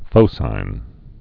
(fōsīn)